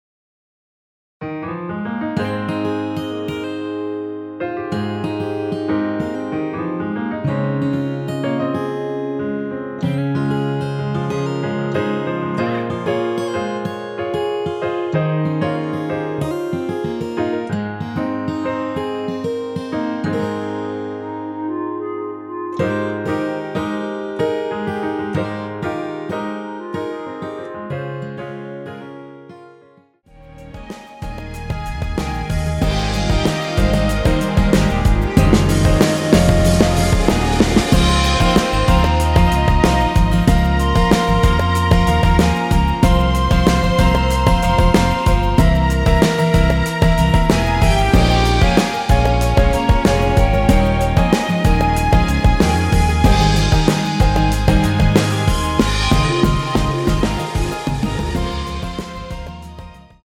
원키에서(+7)올린 멜로디 포함된 MR입니다.
Ab
멜로디 MR이라고 합니다.
앞부분30초, 뒷부분30초씩 편집해서 올려 드리고 있습니다.
중간에 음이 끈어지고 다시 나오는 이유는